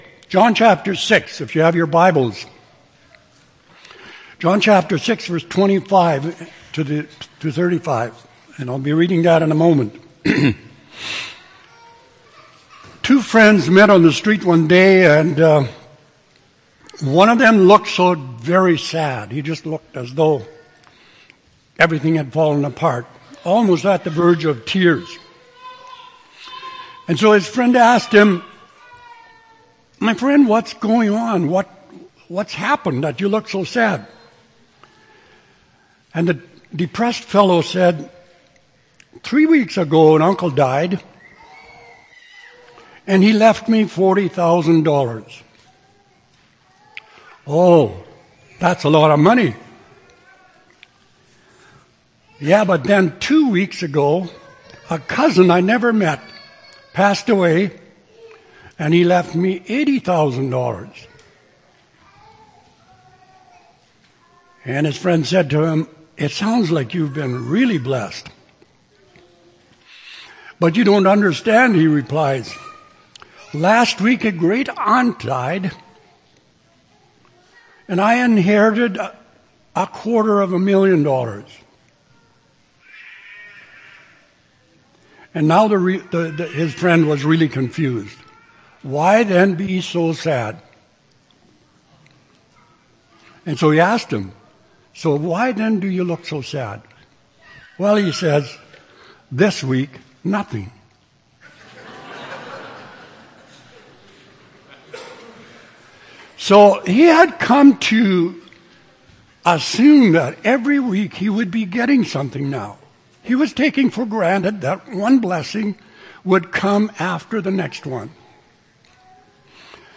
Oct. 12, 2014 – Sermon